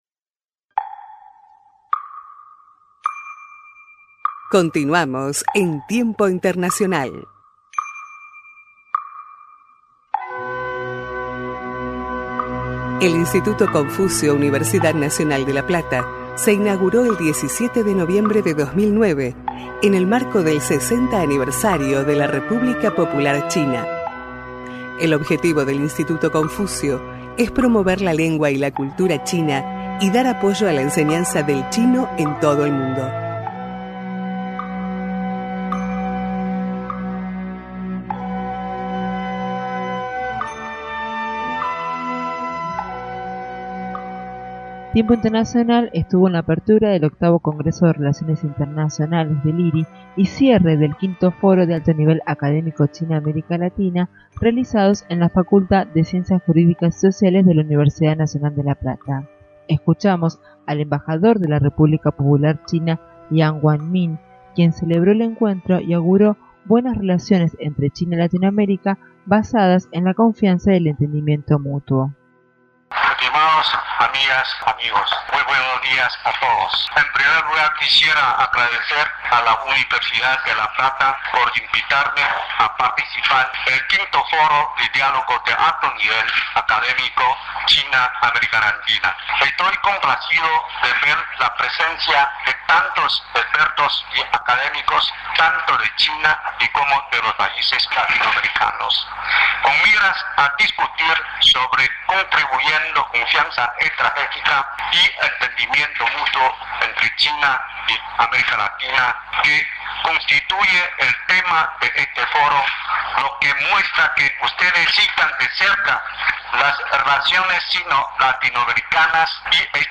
Acto de Apertura del VIII Congreso de Relaciones Internacionales y cierre del V Foro de Alto Nivel Académico de América Latina y China